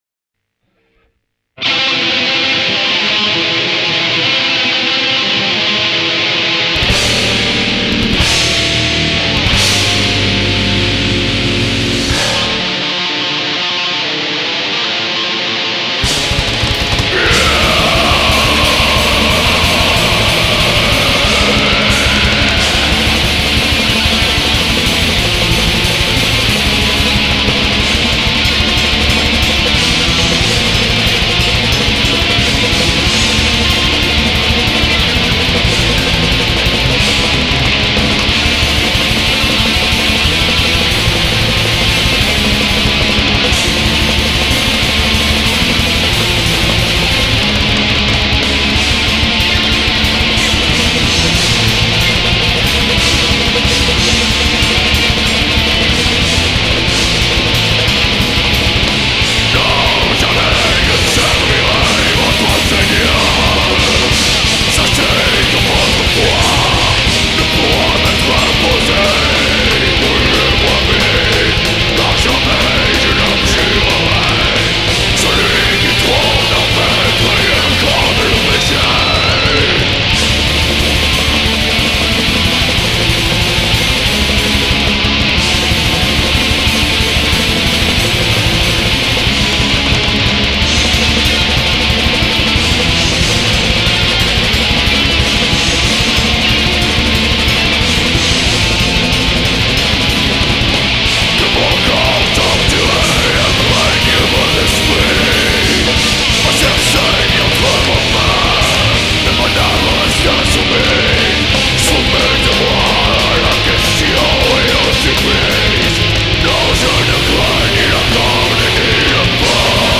[Black/Dark Metal]
Vocals/Guitars
Bass
Drums